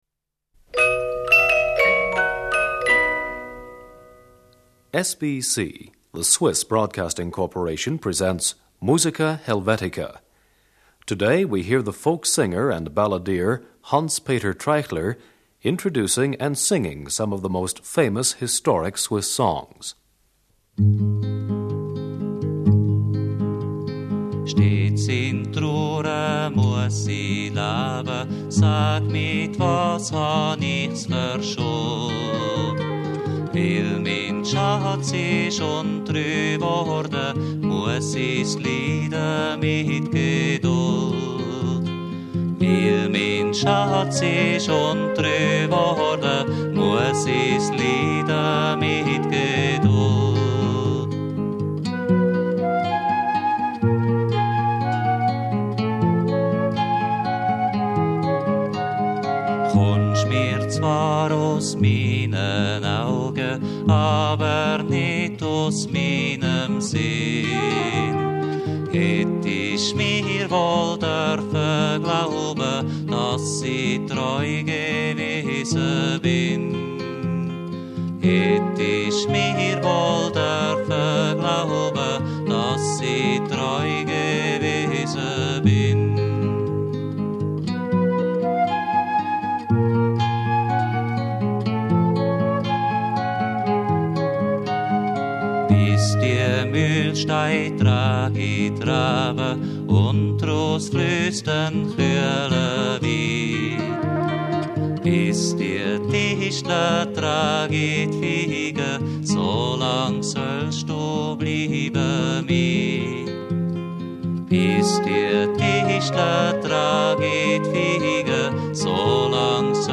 Swiss Folk Music. Historic Folk Songs.
vocal and guitar.
flute.